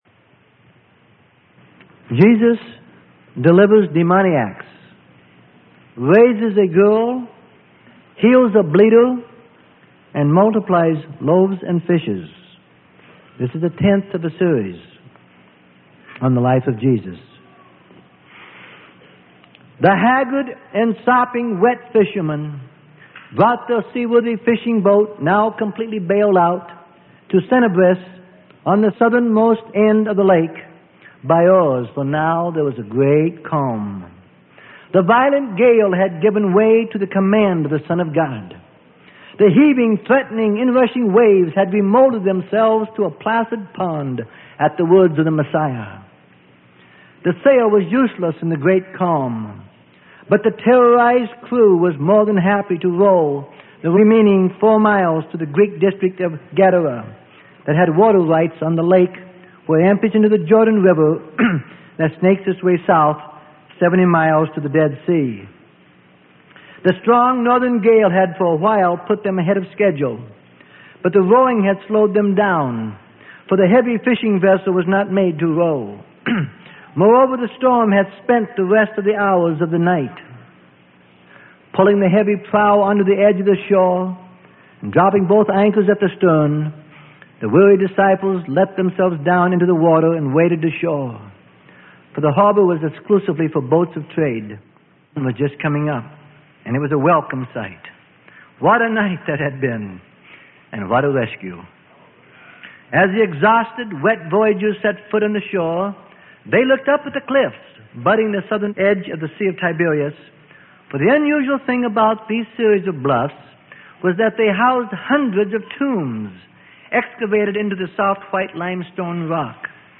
Sermon: The Life Of Jesus.